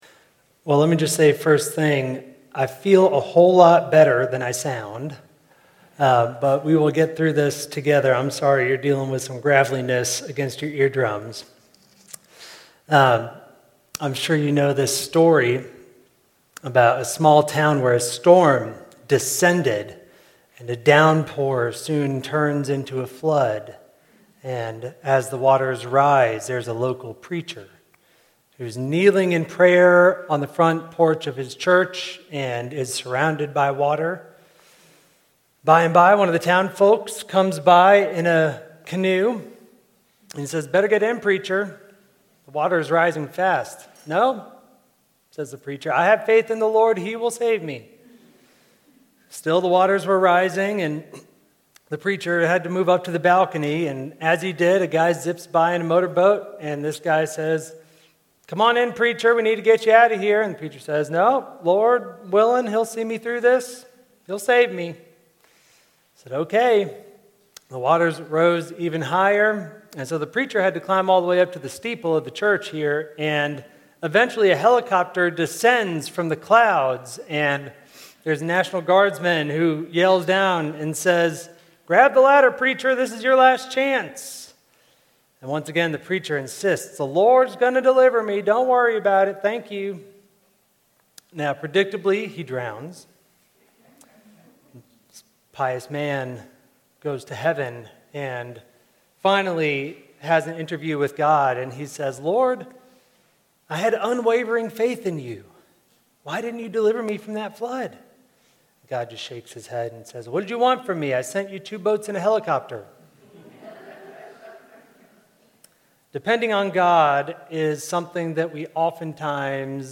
from the First Sunday of Lent